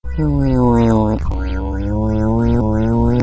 Epic noise - Hmmm mmm AWESOME
Category: Sound FX   Right: Personal